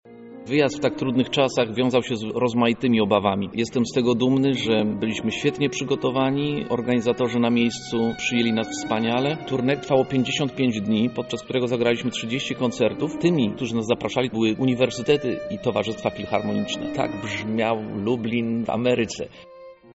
konferencja-filharmonia.mp3